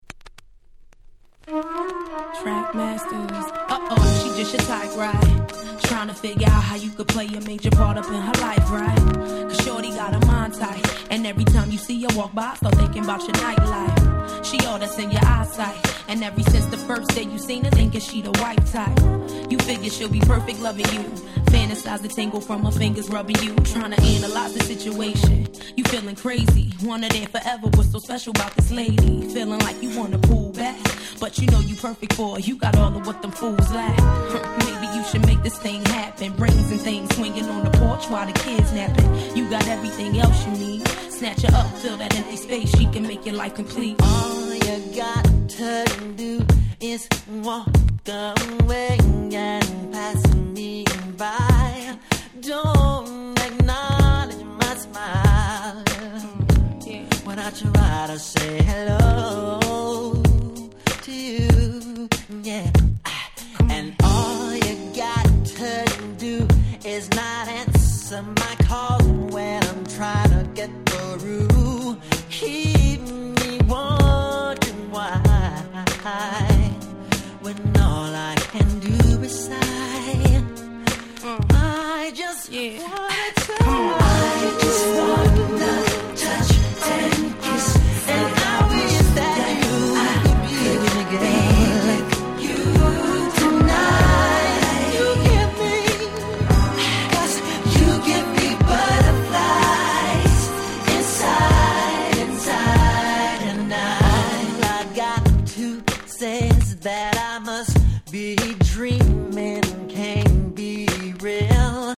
01' Nice R&B !!